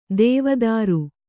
♪ dēva dāru